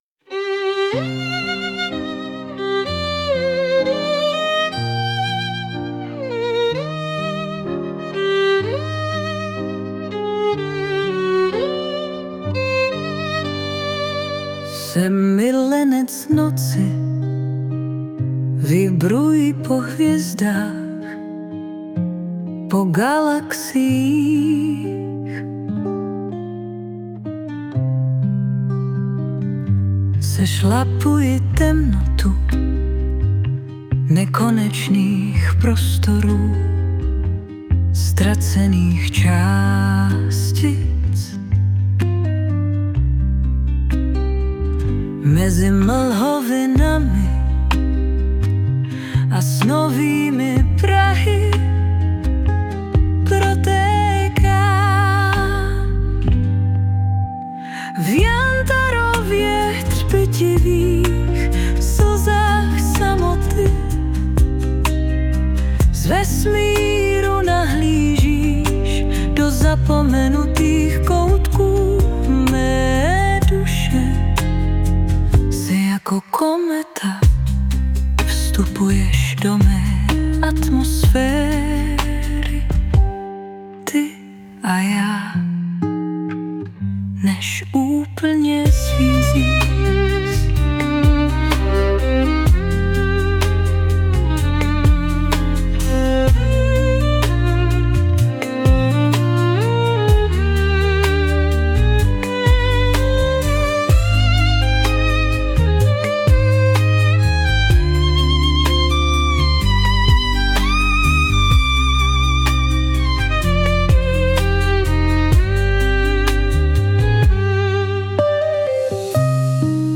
Na základě Tvého pohledu jsem se zkusil projevit i hlasově.
Dal jsem do toho úplně všechno, i přesto že neumím předčítat.
Já mám úplně jiný hlas, než vypadám.